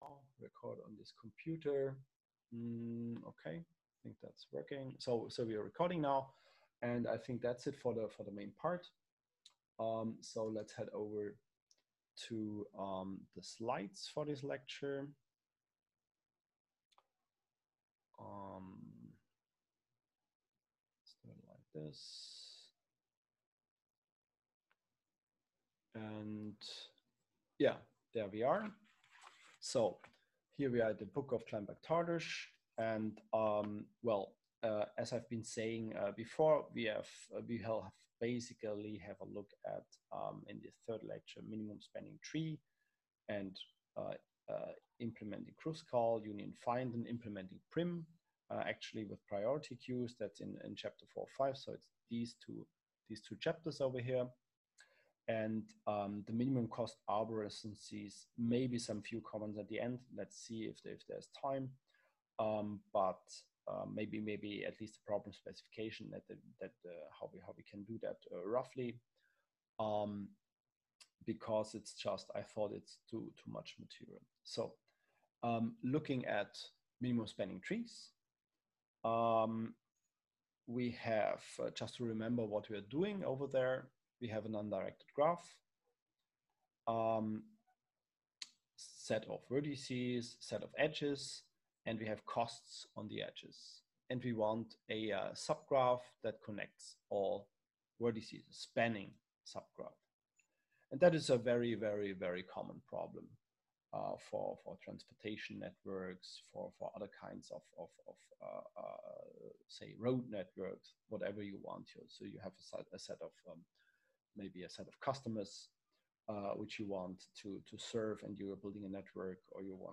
Minimum spanning trees Online lecture
09-lecture.m4a